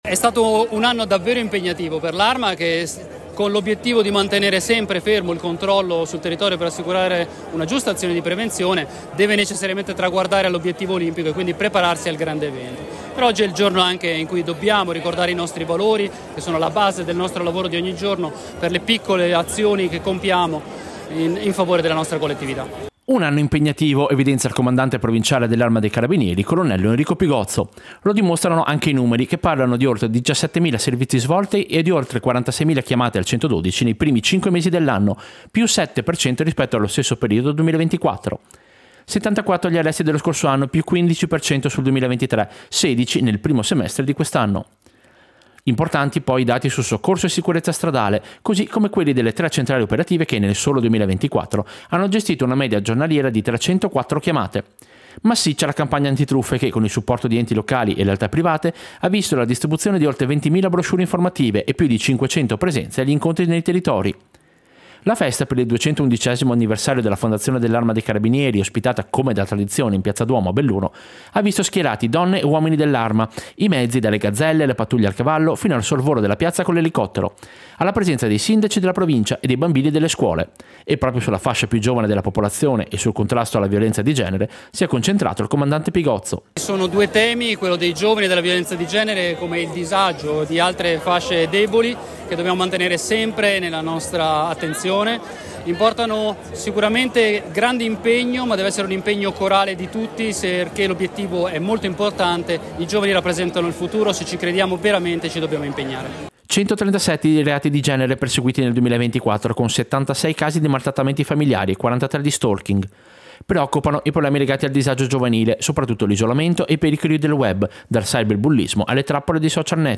Servizio-Festa-Carabinieri-2025.mp3